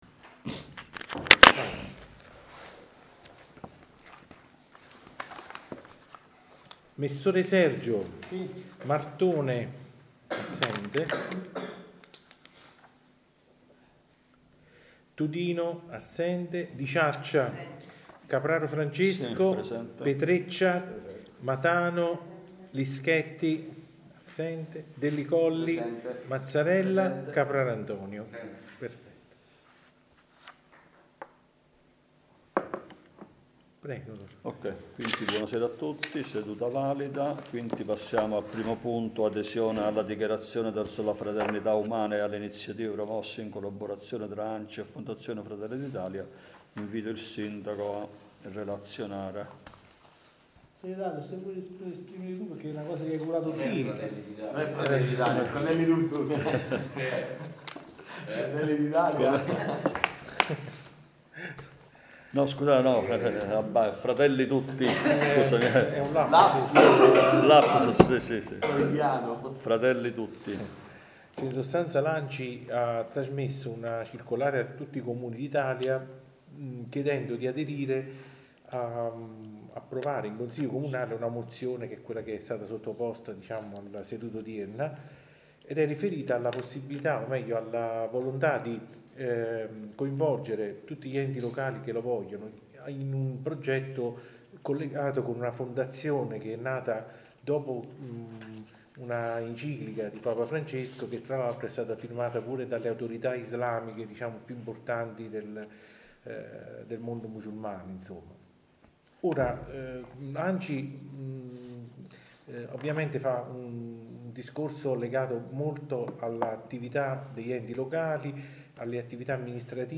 Registrazione seduta consiliare del 14.2.2025